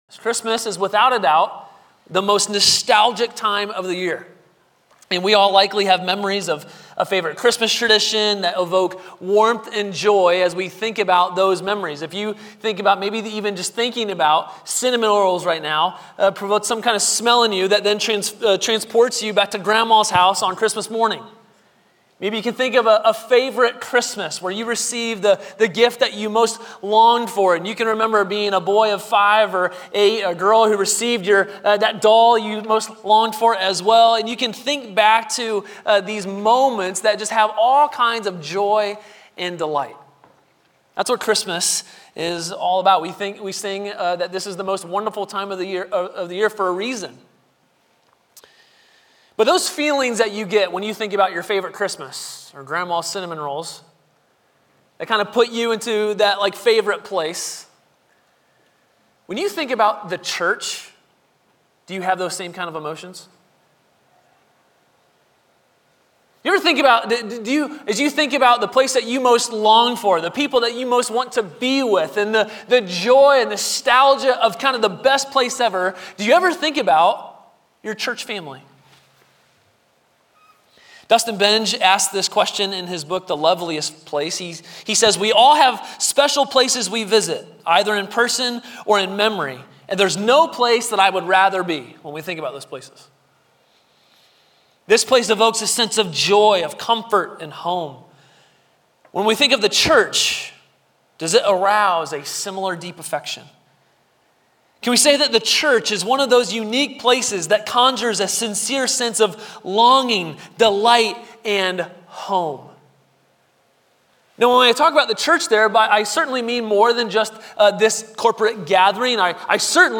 All Sermon Series • Grace Polaris Church